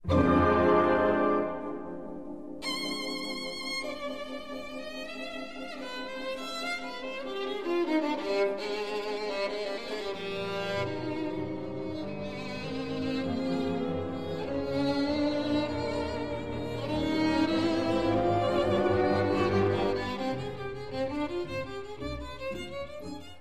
Allegro moderato
violin, BBC Symphony Orchestra, cond. Jiri Belohlavek